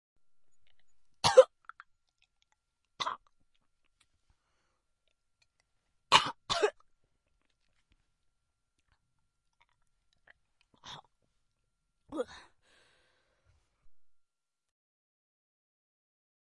女声干呕咳嗽音效免费音频素材下载